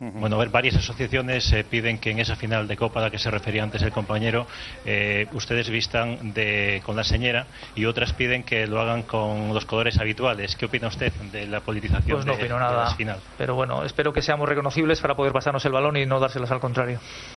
El técnico respondió a las peticiones que hay de que el Barça juegue con la camiseta con los colores de la senyera en al final de Copa del sábado: "Espero que seamos reconocibles para poder pasarnos el balón y no dársela al contrario"